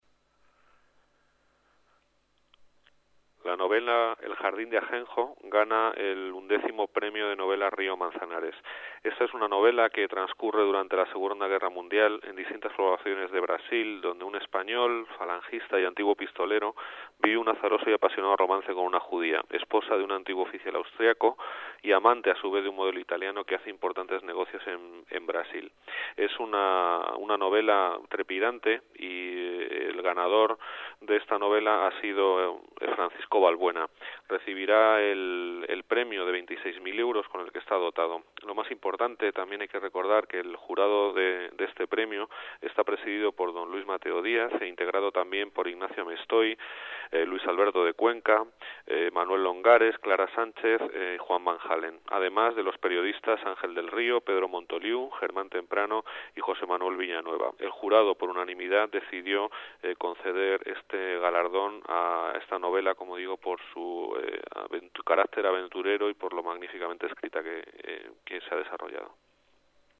Nueva ventana:Declaraciones de Juan José de Gracia, coordinador general de Vivienda